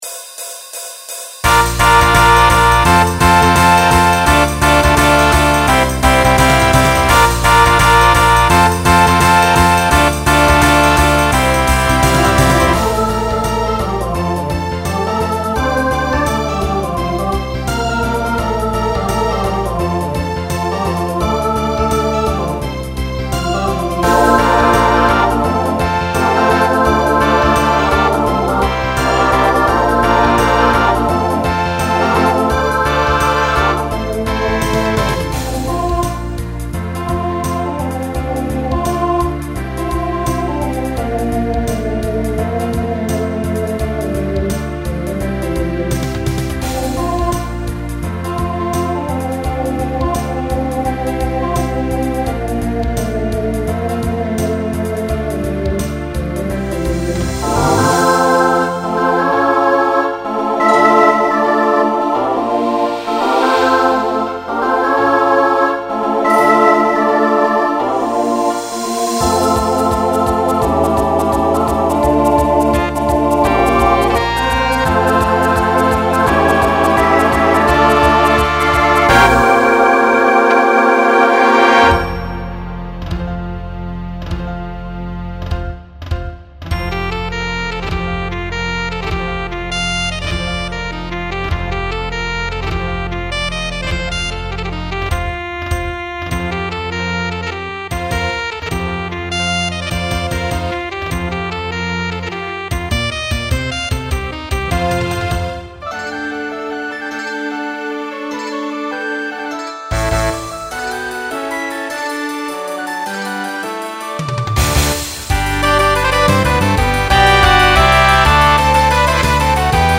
Genre Rock
Transition Voicing SATB